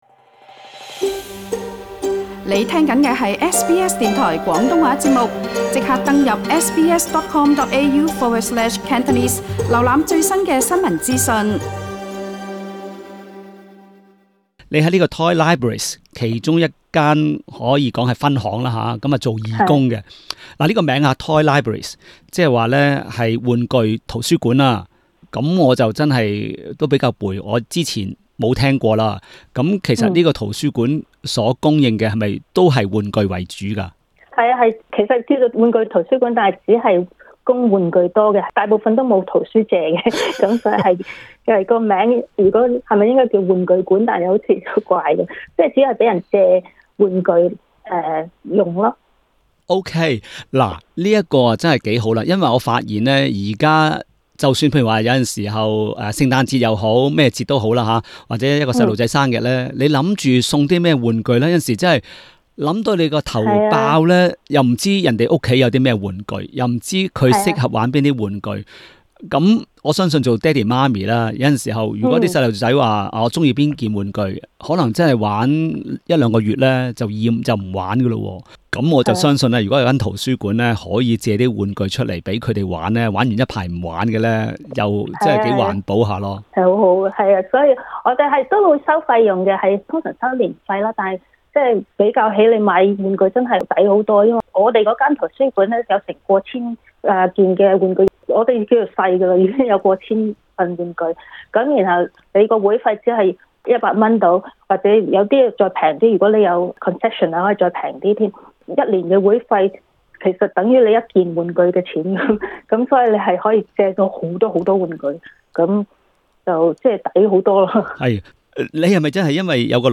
不過今天一位在玩具圖書館做義工的聽眾，為大家介紹這些玩具圖書館為大家提供的玩具服務。